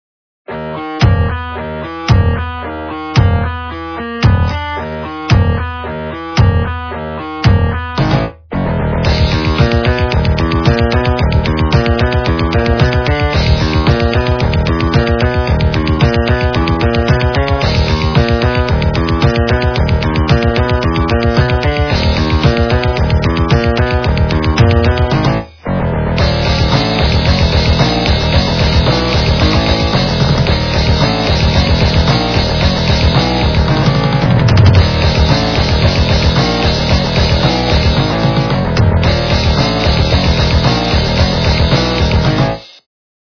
- рок, металл
полифоническую мелодию